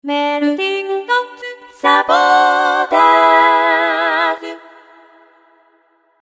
メルサポートのサウンドロゴを作ってみた。